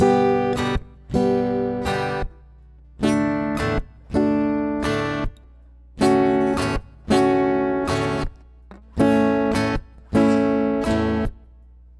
guitar - juicy 160.wav